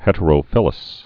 (hĕtə-rō-fĭləs)